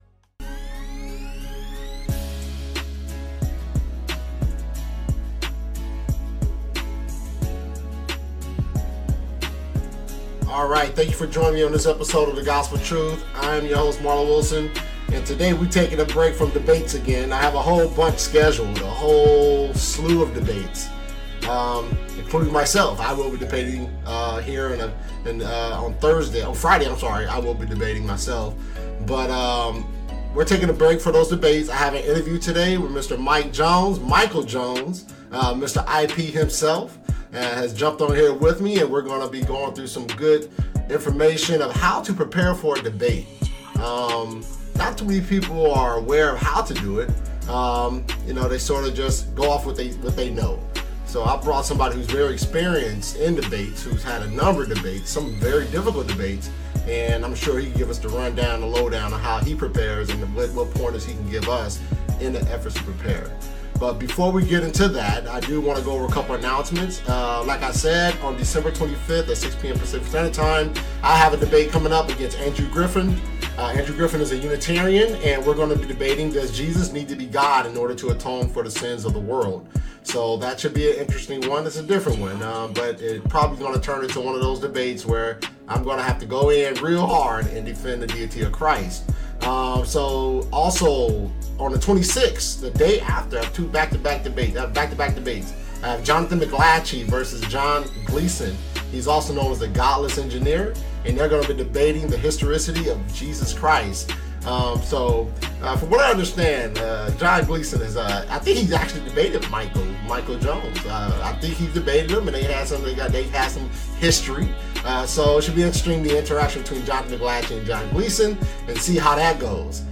Interview: How to Prepare for a Debate.